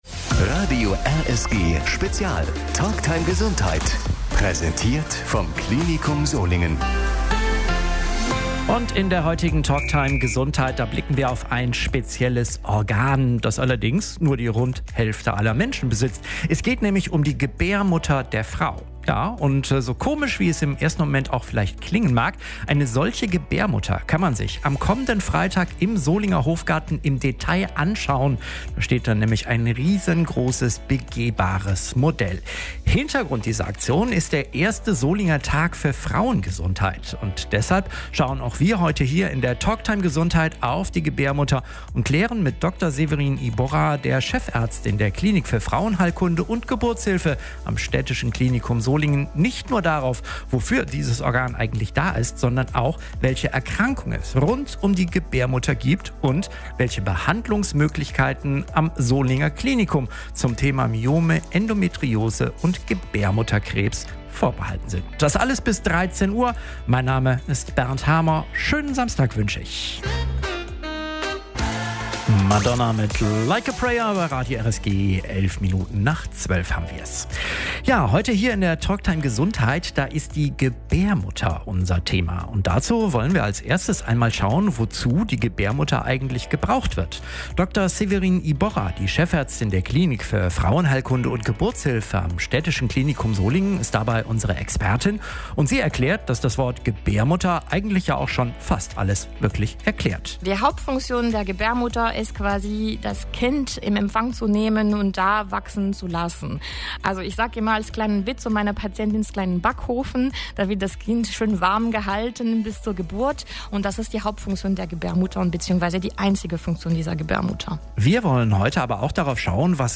Um Gebärmutter-Erkrankungen und den "Tag der Frauengesundheit" ging es am 28. Februar in der Radiosprechstunde.
Die Sendung zum Nachhören